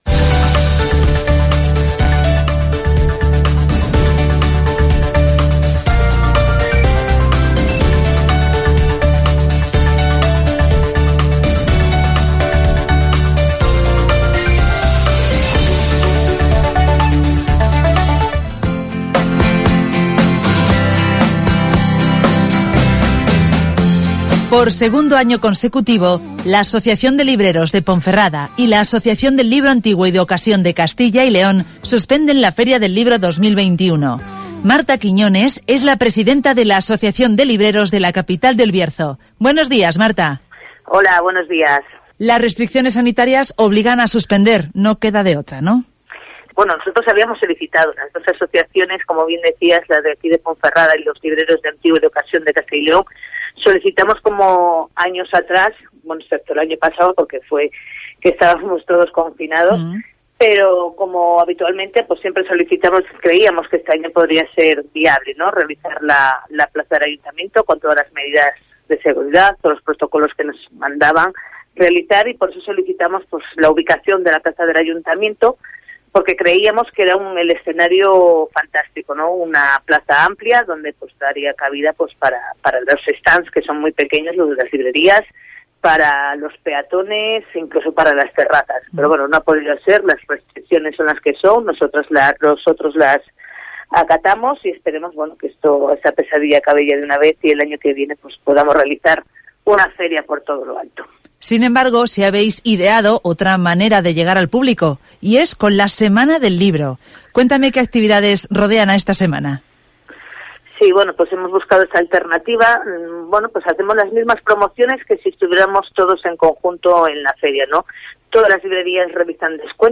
Ponferrada celebra el Día Internacional del Libro con distintos descuentos y sorteos en la Semana del Libro (Entrevista